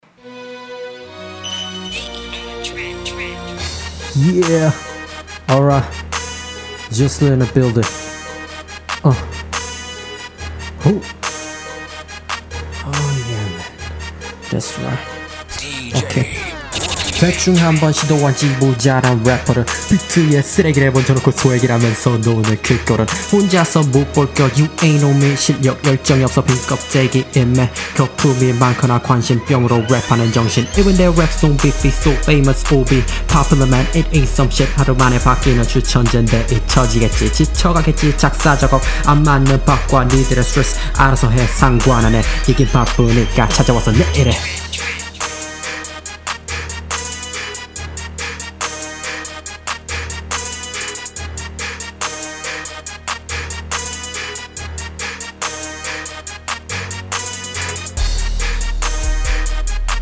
정말 믹싱이...-_-죄송합니다ㅜ
약간 느낌을 내보려고 했어요(전 T.I팬인데ㅋ)
코가 좀 많이 막히신건가 정말 조용히 뱉어서 그런건가;; 좀 답답하게 들리네요
빠른 혀놀림 나름 루다 느낌있어요 ㅎ
가사는 빠른 플로우가 나오게 잘 쓰셨어요
지금 느낌은... 거실에서 가족이 들을까봐 방에서만 들릴 정도로 녹음한 느낌?